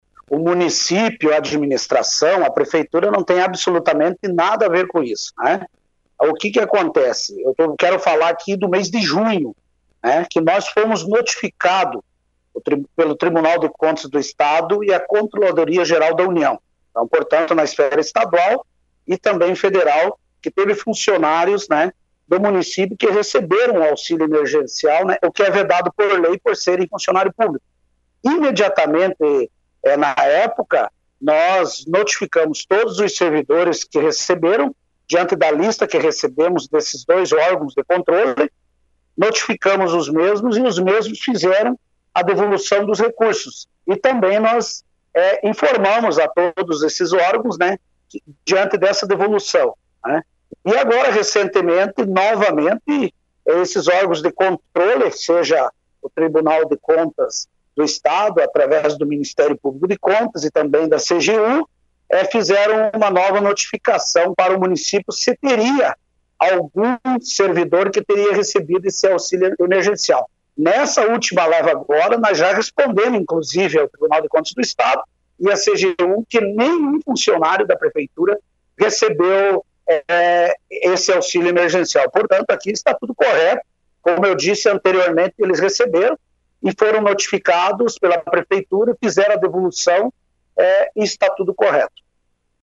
Em entrevista ao Jornal Colmeia desta quarta-feira, 21 de outubro, o prefeito Raul Ribas Neto esclareceu a situação.
PREFEITO-DE-MATOS-COSTA-RAUL-RIBAS-NETO.mp3